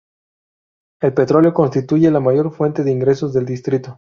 Hyphenated as in‧gre‧sos Pronounced as (IPA) /inˈɡɾesos/